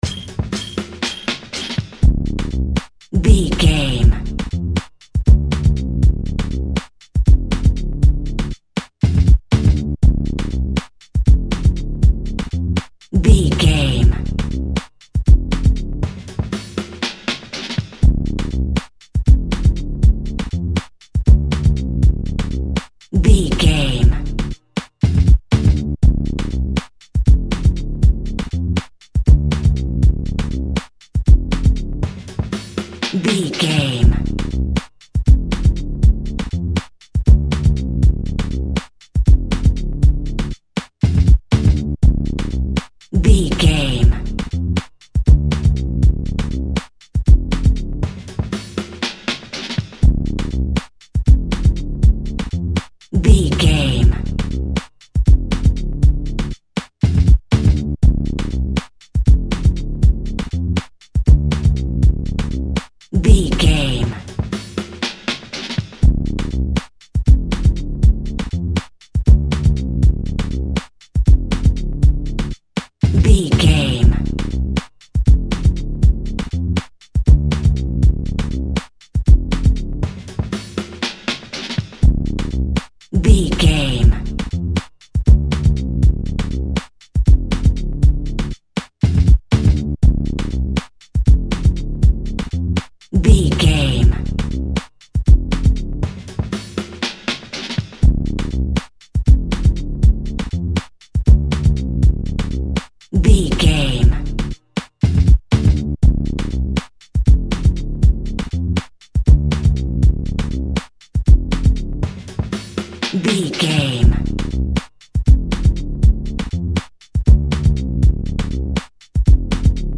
Electronic Electro Electronica.
Epic / Action
Fast paced
In-crescendo
Aeolian/Minor
aggressive
dark
drum machine
techno
trance
industrial
glitch
synth lead
synth bass
electronic drums
Synth Pads